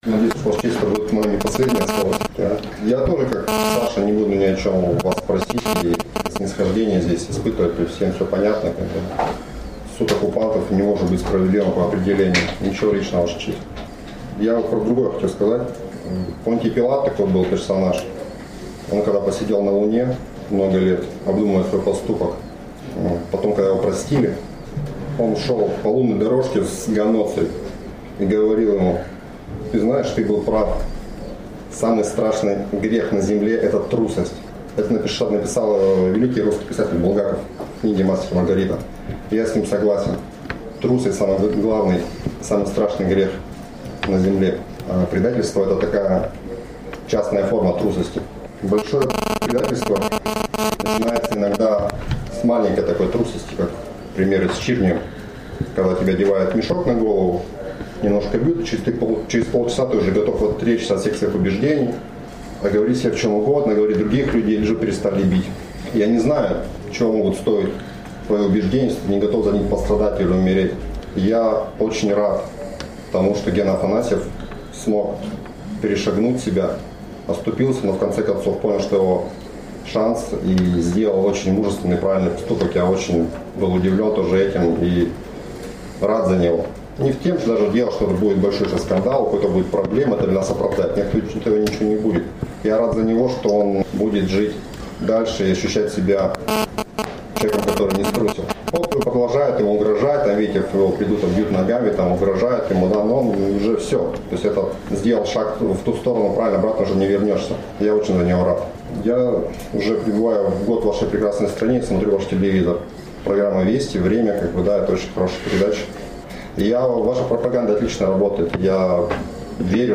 Защита призвала судейскую коллегию полностью оправдать обвиняемых, которые выступили с последним словом.